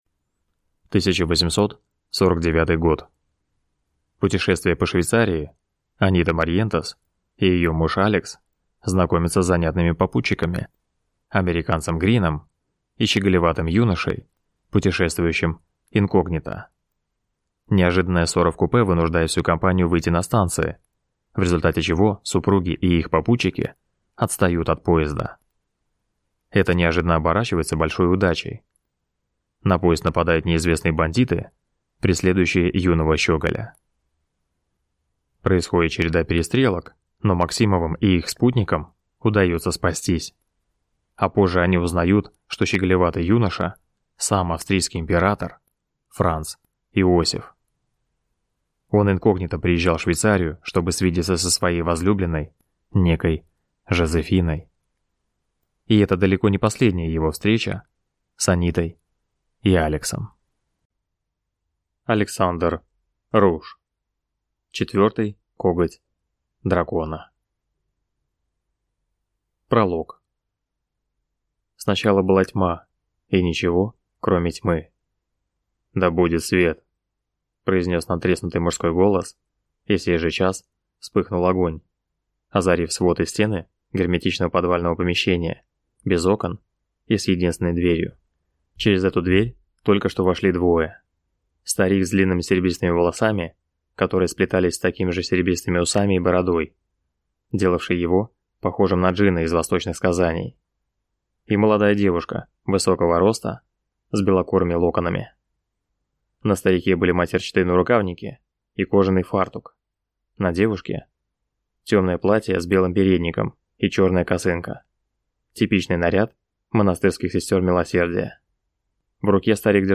Аудиокнига Четвертый коготь дракона | Библиотека аудиокниг